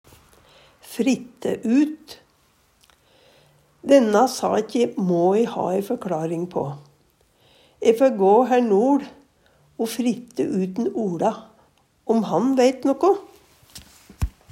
fritte ut - Numedalsmål (en-US)
DIALEKTORD PÅ NORMERT NORSK fritte ut spørja ut Eksempel på bruk Denna sakje må e ha ei førkLaring på.